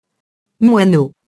moineau [mwano]